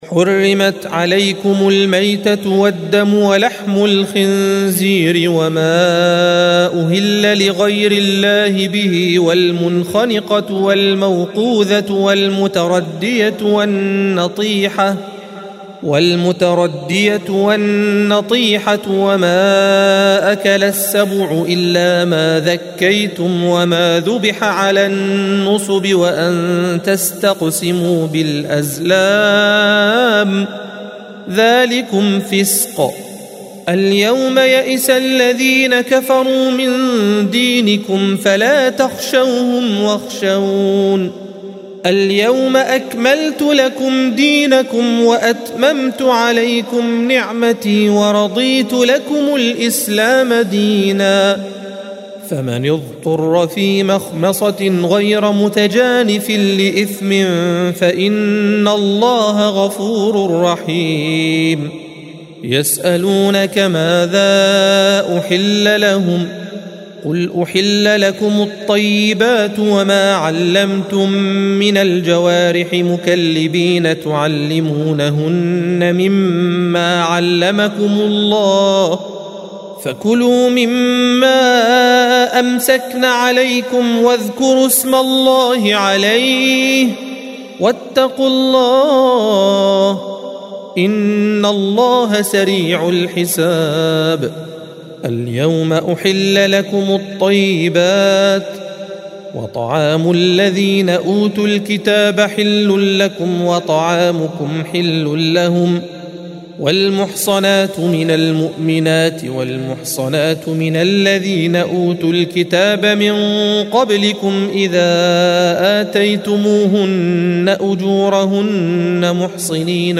الصفحة 107 - القارئ